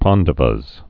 (pändə-vəz)